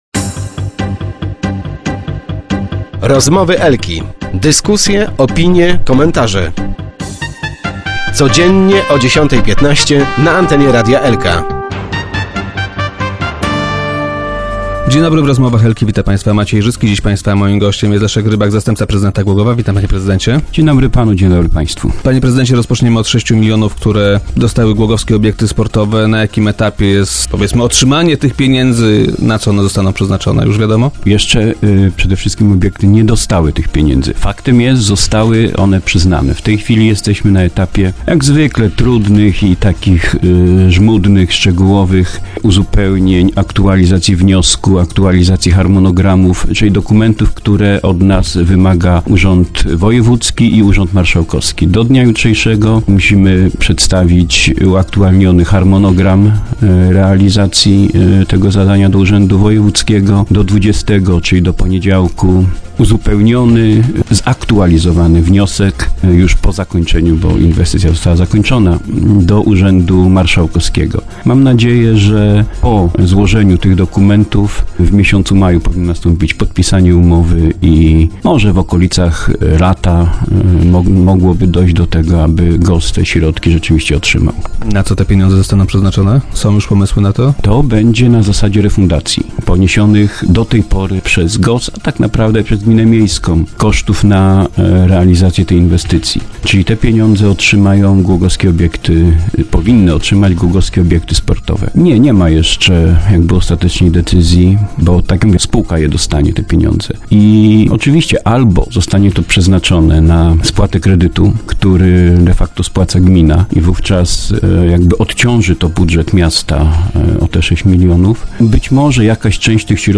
- Wykonawca projektu nie miał argumentów, tłumaczących opóźnienie, poniesie więc tego konsekwencje - powiedział nam wiceprezydent Rybak, który był dziś gościem Rozmów Elki.